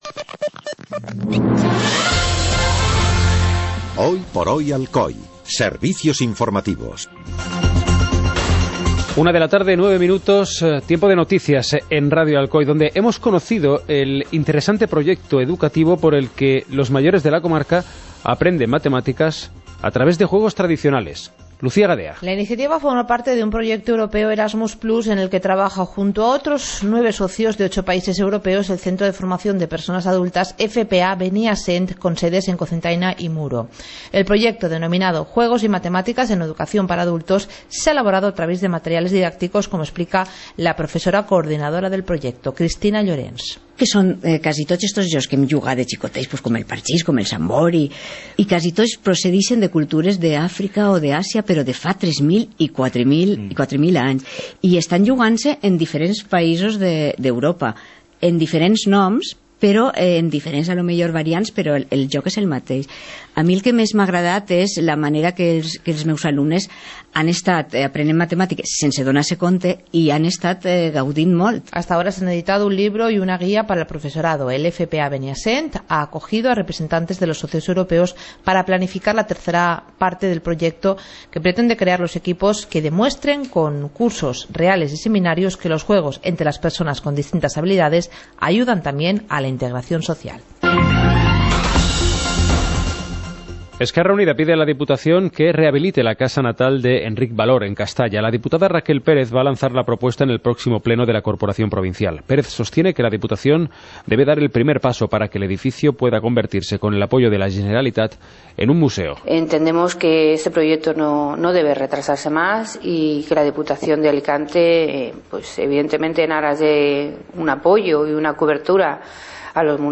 Informativo comarcal - lunes, 03 de abril de 2017